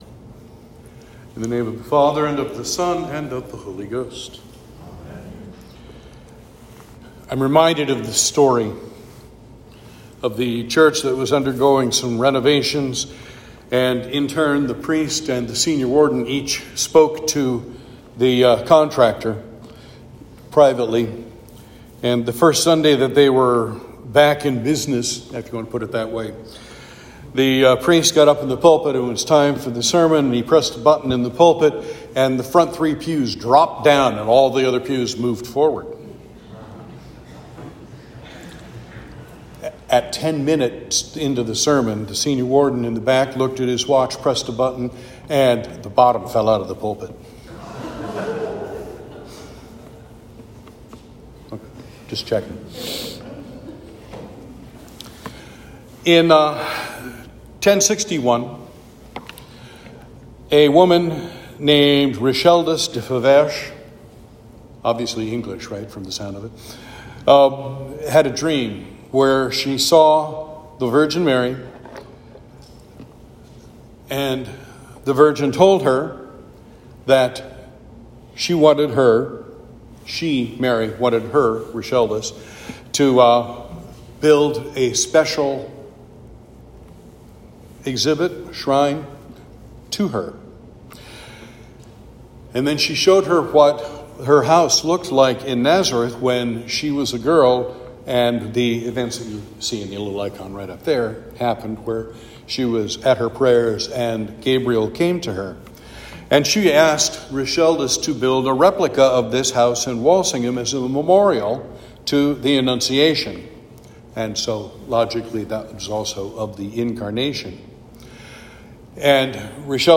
Sermon for Trinity 19